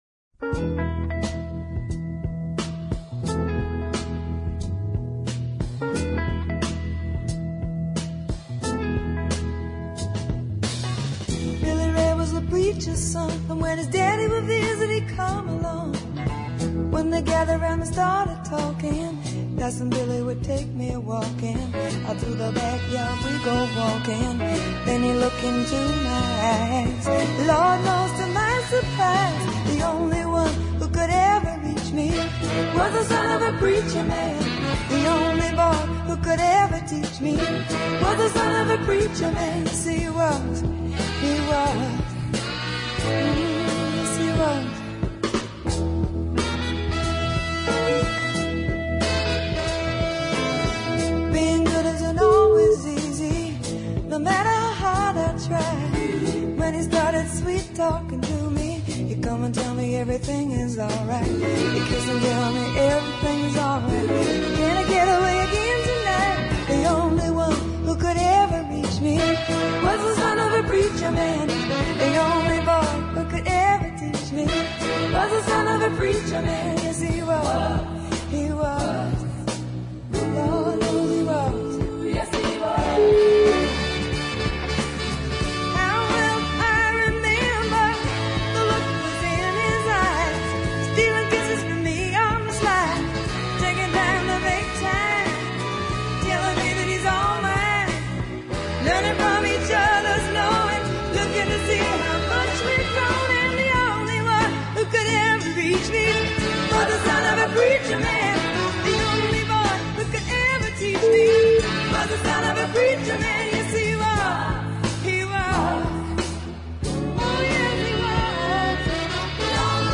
Some people's choice as the best white female voclaist.
breathy, panting vocals